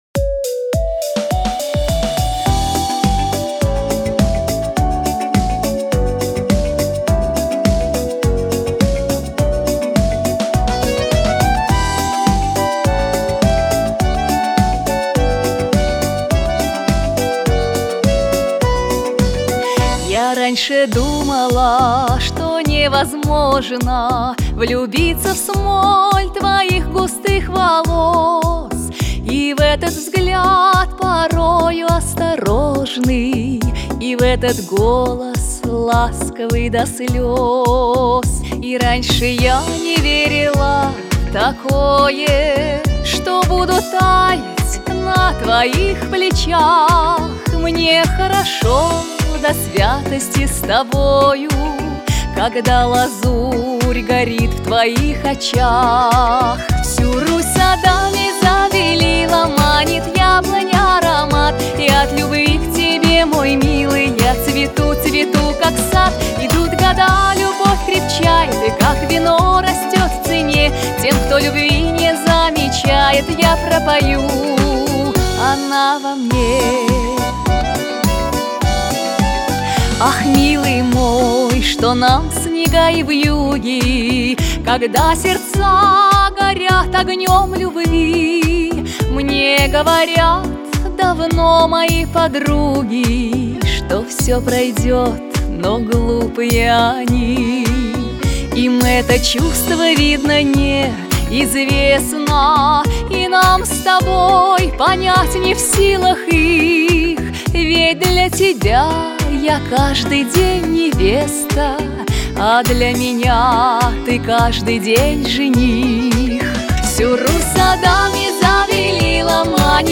диско , pop
эстрада